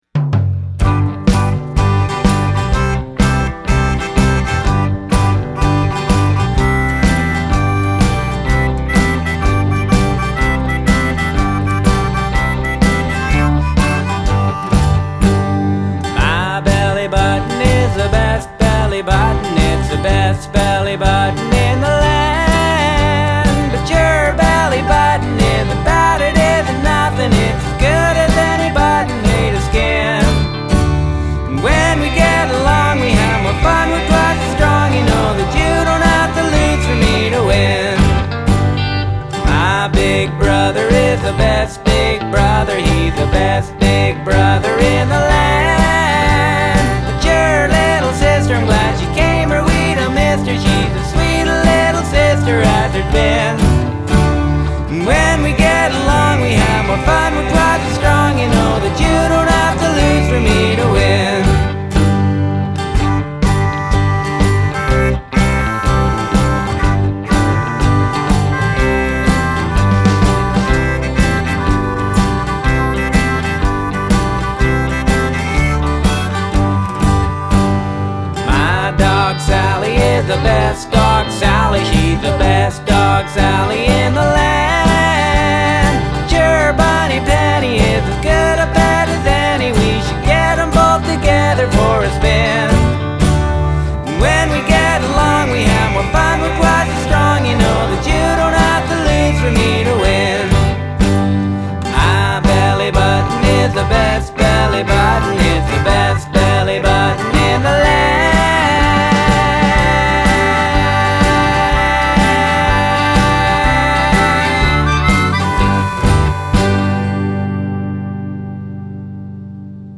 electric guitar, harmonica, vocals
bass, vocals
drums